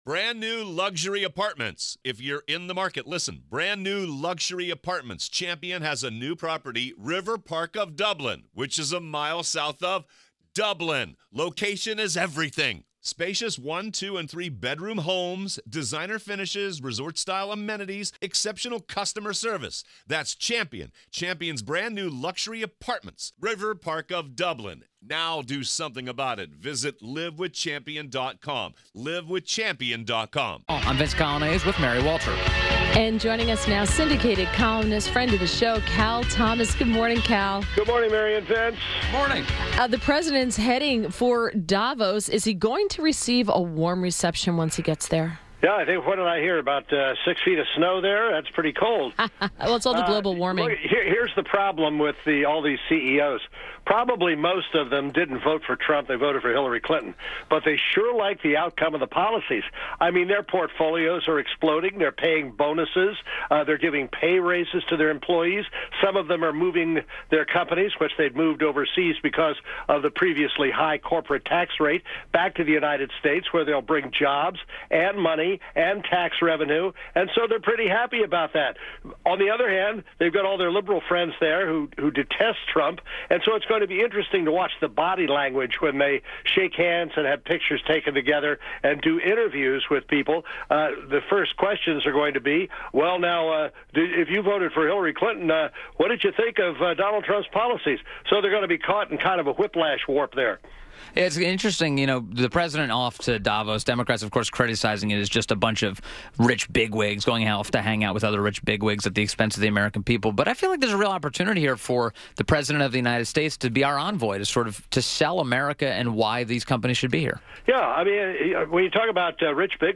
WMAL Interview - CAL THOMAS - 01.24.18